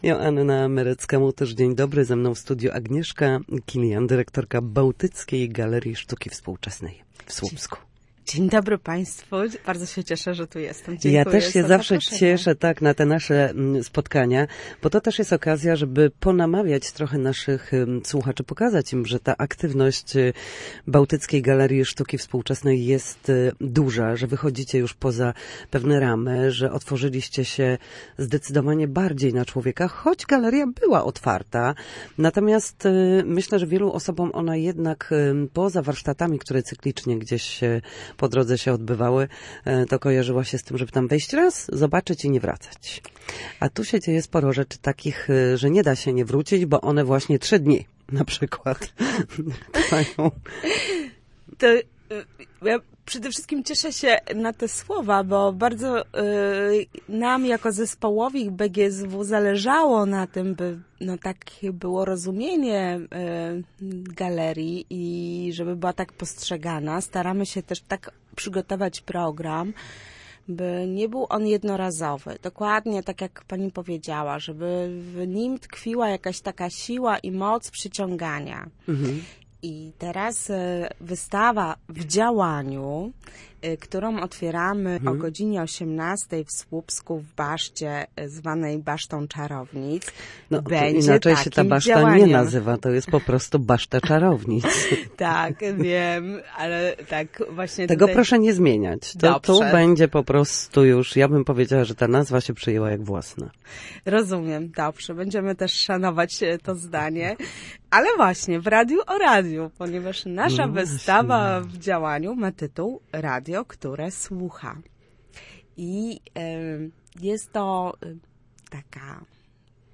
Na antenie Studia Słupsk zapraszała na wydarzenia, które w najbliższym czasie odbędą się w Baszcie przy ulicy F. Nullo w Słupsku.